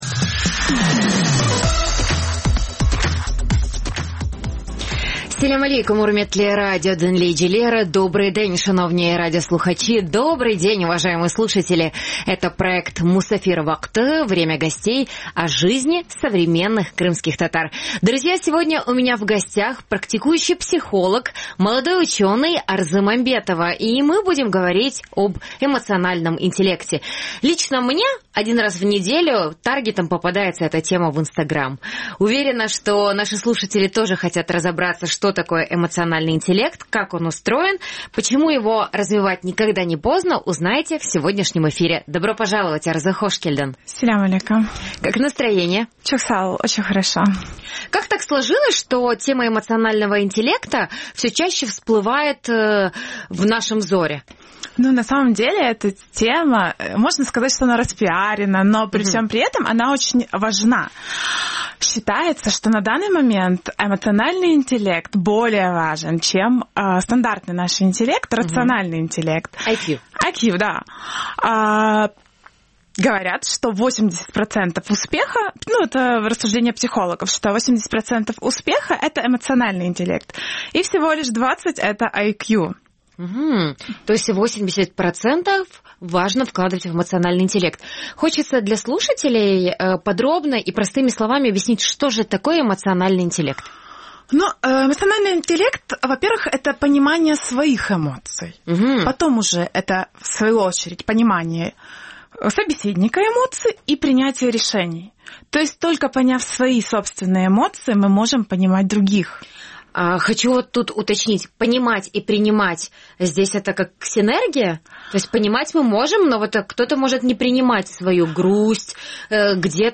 Развитие эмоционального интеллекта – разговор с психологом | Musafir vaqtı